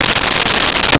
Unzip Metal Zipper 02
Unzip Metal Zipper 02.wav